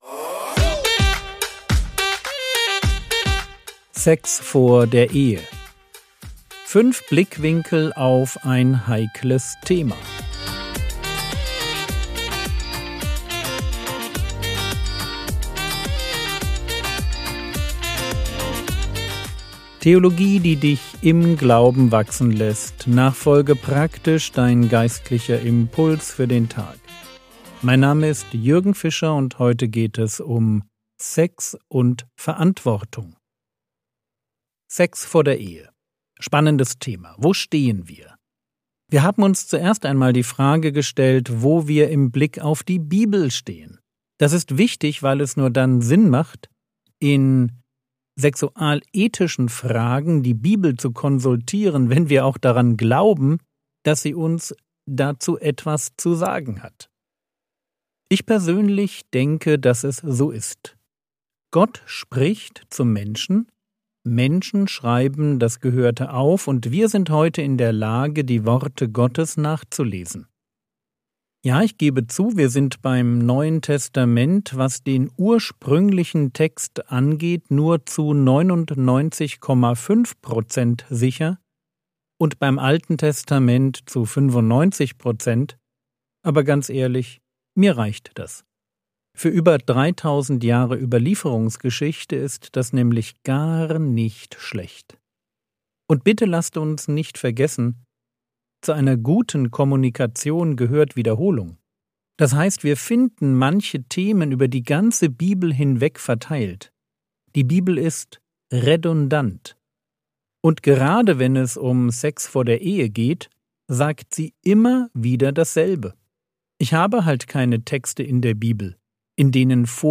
Mini-Predigt